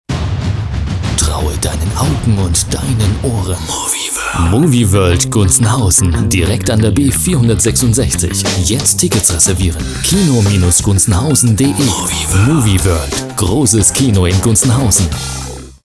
Sprecher deutsch für Radiowerbung, Tv-Werbung, Pc-Spiele, Industriefilme...
Sprechprobe: Werbung (Muttersprache):
german voice over talent